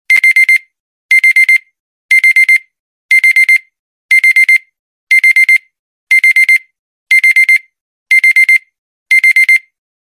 LG (Life's Good) Default Timer sound effects free download